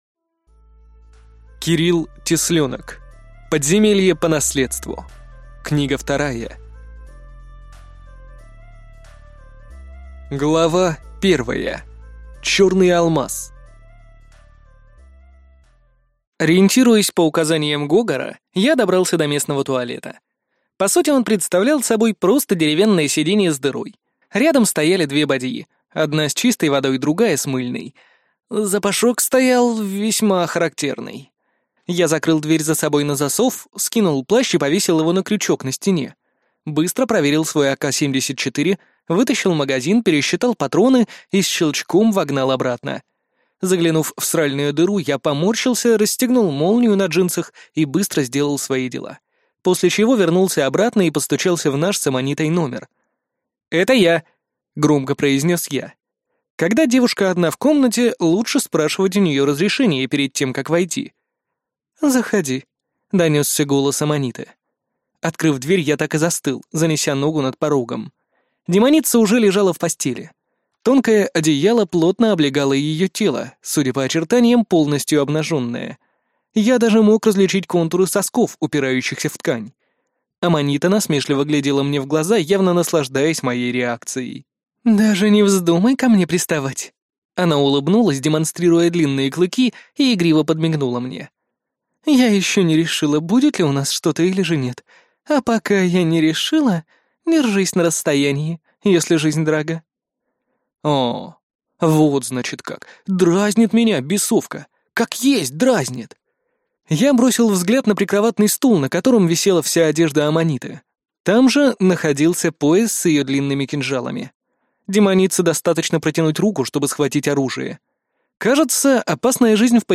Аудиокнига Подземелье по наследству. Книга 2 | Библиотека аудиокниг